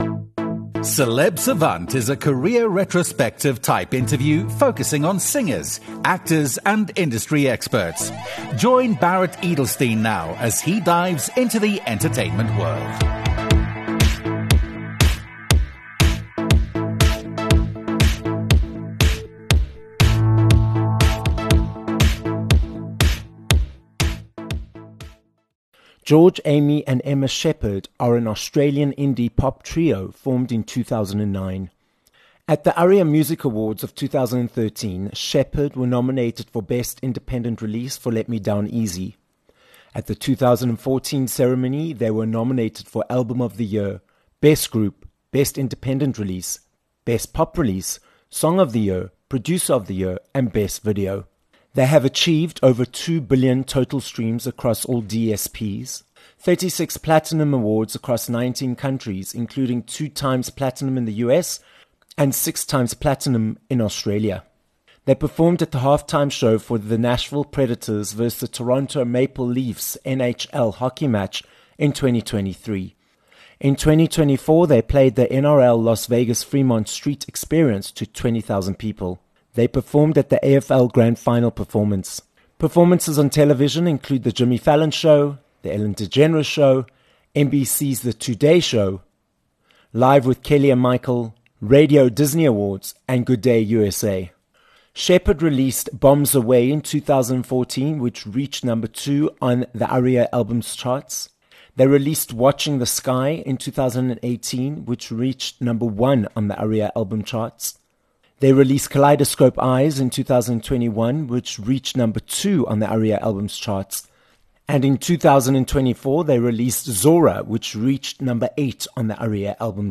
Sheppard - an Australian-born, Nashville-based indie pop band - is the guest on this episode of Celeb Savant.